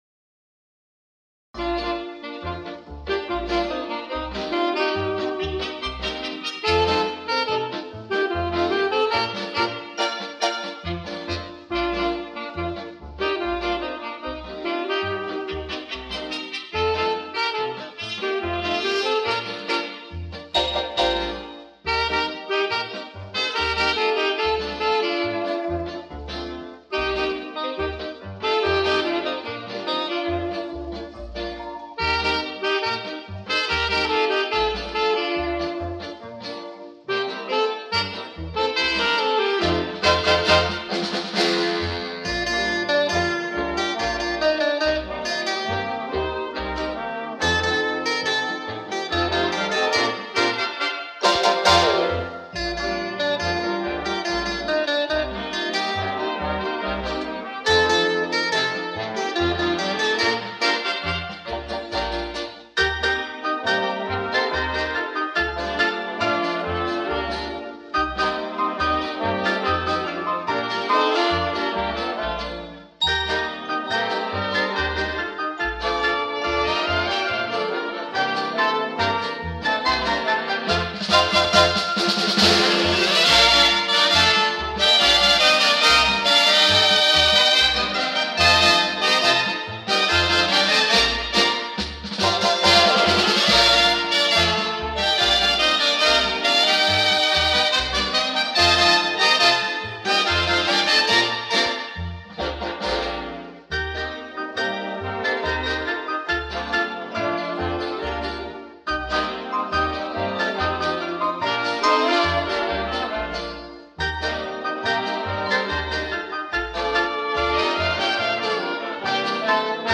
Звук нормализован но пикам, а не по средней громкости.